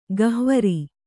♪ gahvari